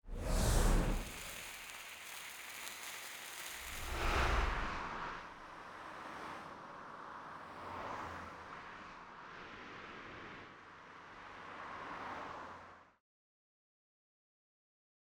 FORD_UNSUNG_Premiere_v05_ST_SFX.wav